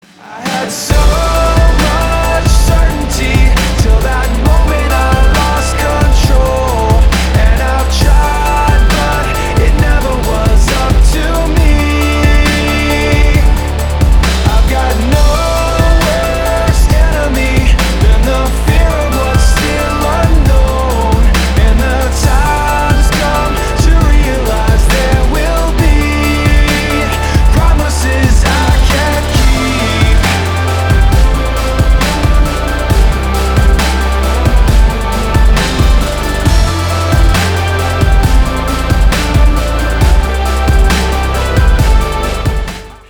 • Качество: 320, Stereo
Rap-rock
Alternative Rock
Alternative Hip-hop